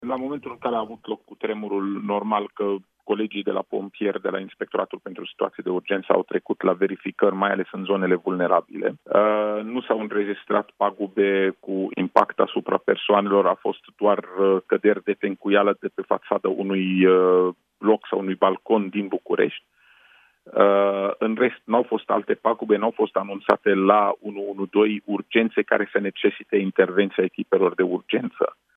În București, o căzut tencuiala de pe fațada unui bloc a mai spus Raed Arafat la Europa FM, în această dimineață: